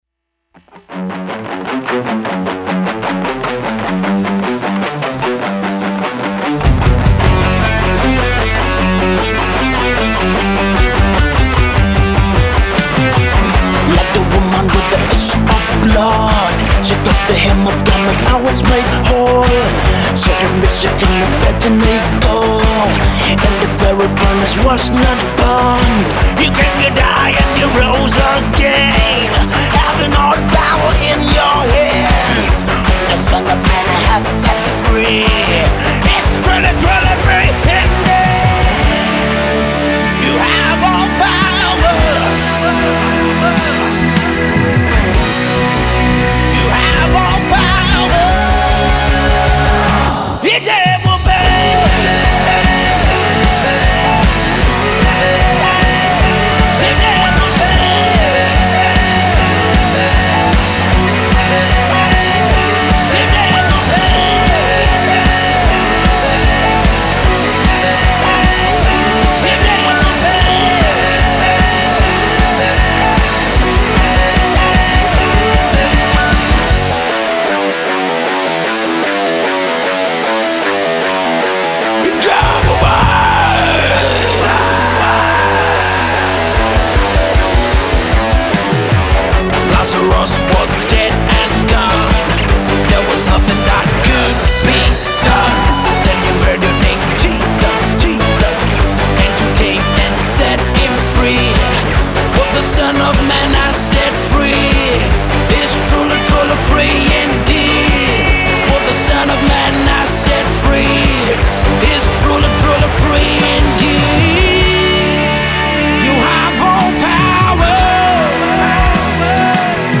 a Nigerian based energetic Christian rock boy-band